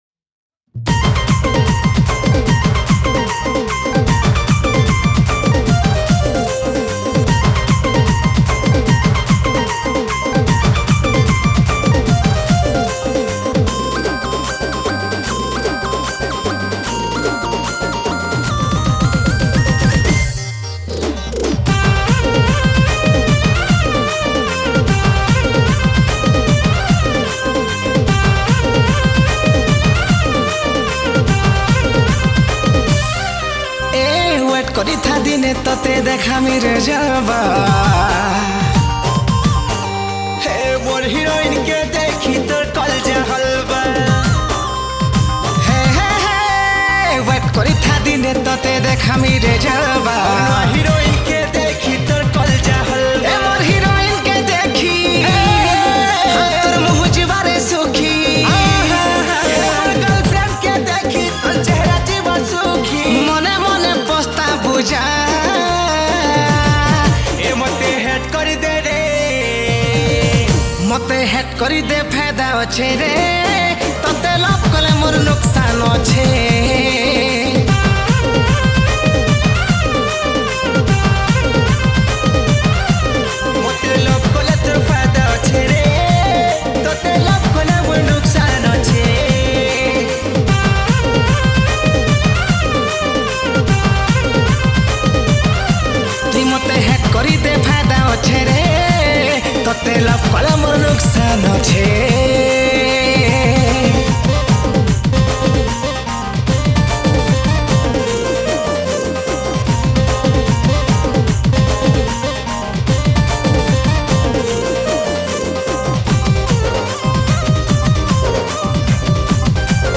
Category: New Sambalpuri Songs 2022